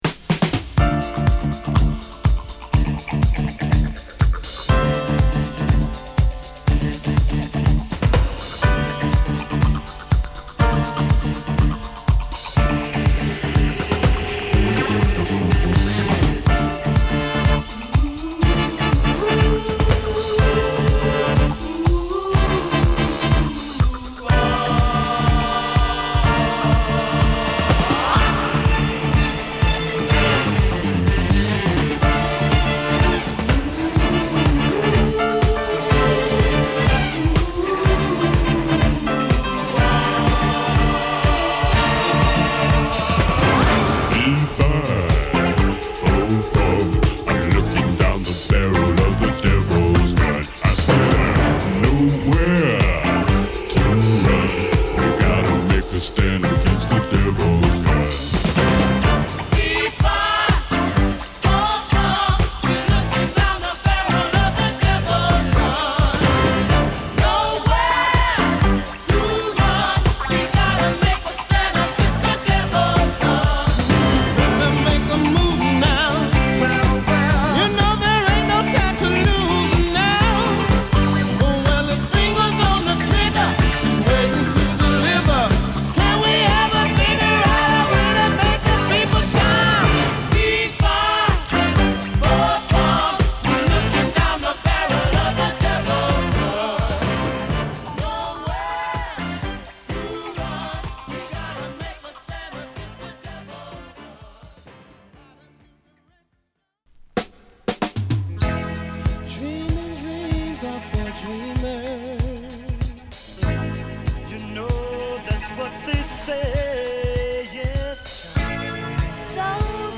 は疾走感溢れるドラムに情熱的なストリングス&ホーンが映えるガラージュ・クラシック！